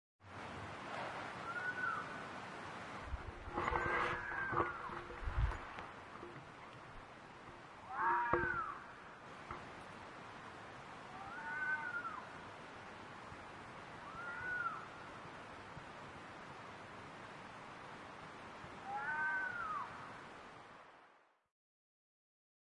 Fox Noises